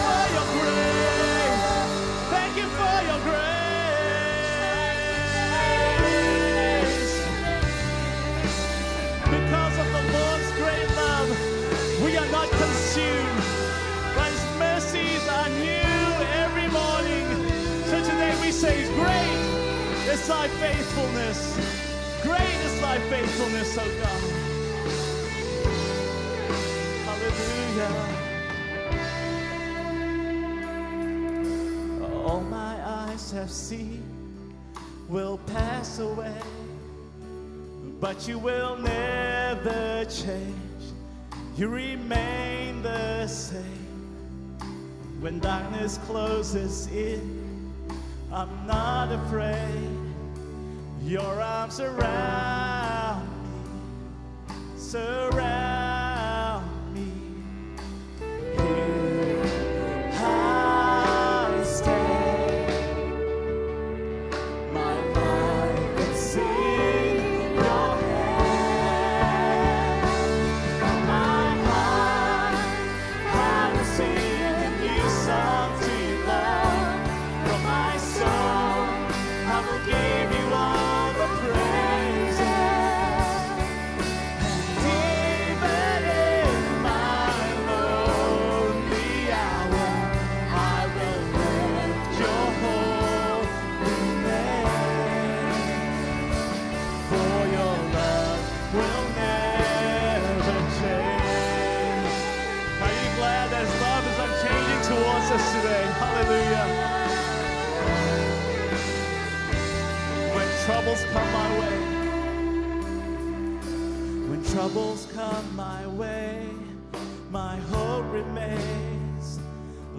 Finally, on the Sunday just past, without much pomp or ceremony, we quietly introduced the song to the congregation.
And ultimately, the arc was finally completed as the congregation gave voice to the song.
So here is the live recording of “Your Love” as sung last Sunday.
Key: G